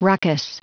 Prononciation du mot ruckus en anglais (fichier audio)
Prononciation du mot : ruckus